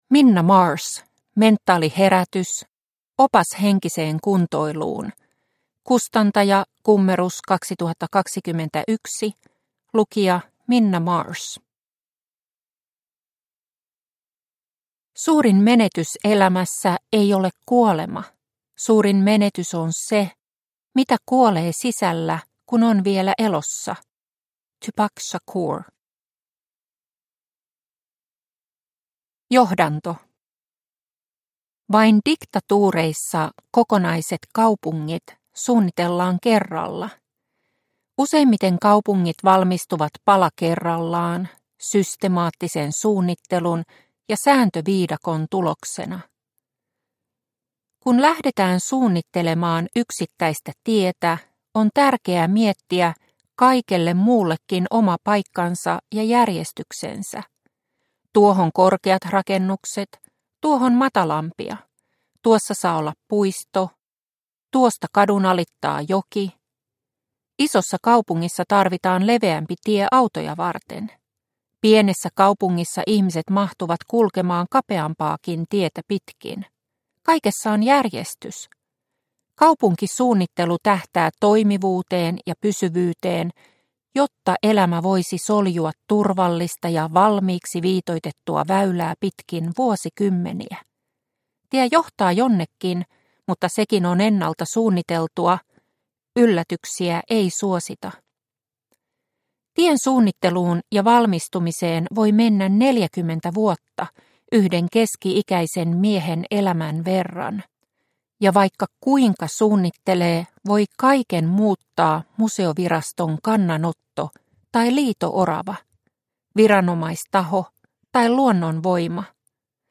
Mentaaliherätys! – Ljudbok – Laddas ner